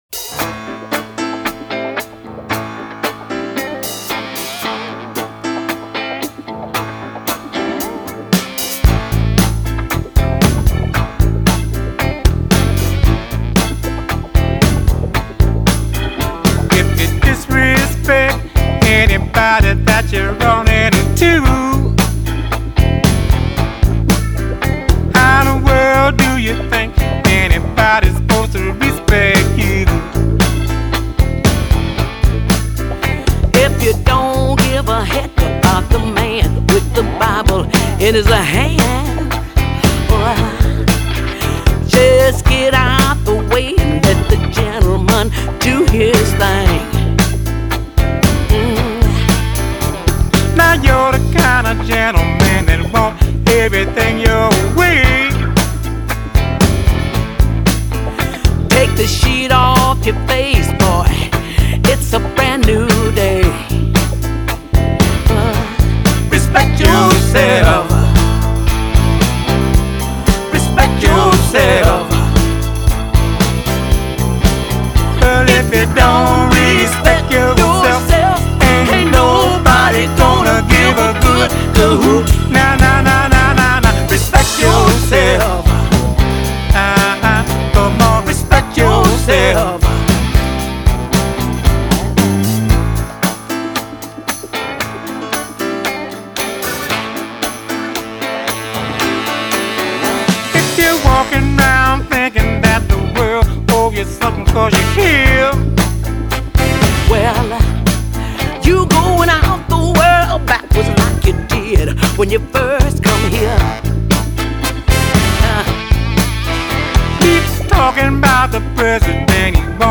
Genre: Soul
with completely uncontrived tenderness